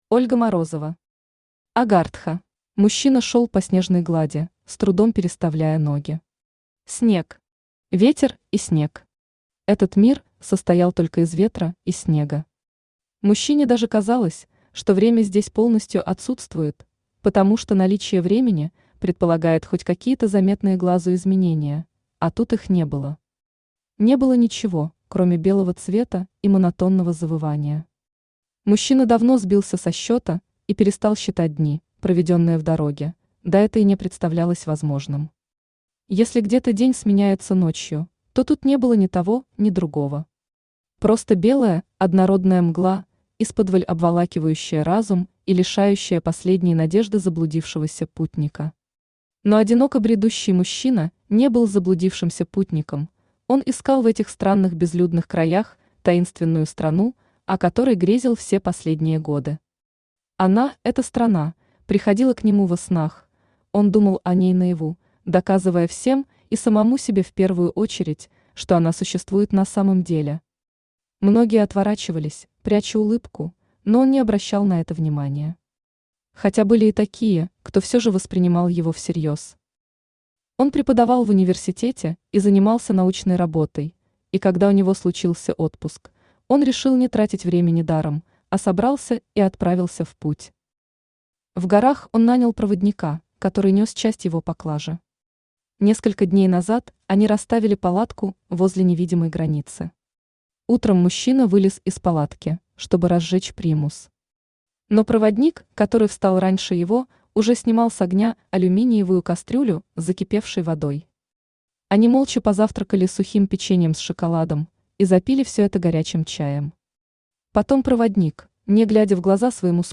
Аудиокнига Агартха | Библиотека аудиокниг
Aудиокнига Агартха Автор Ольга Юрьевна Морозова Читает аудиокнигу Авточтец ЛитРес.